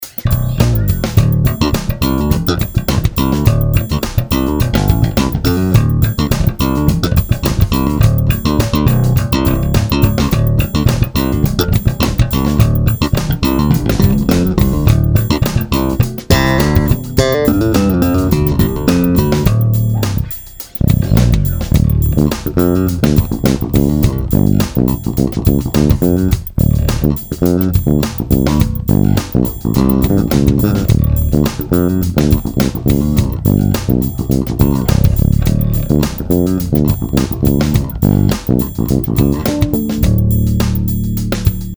ted ty ukazky :)) EQ je zatim narovno,lehka komprese v kompu. samotny MM a J snimac mozna nahraju pozdeji,ale neni to zadny zazrak :D v ukazkach je blend presne na stredu
MM+J - slap, pak prsty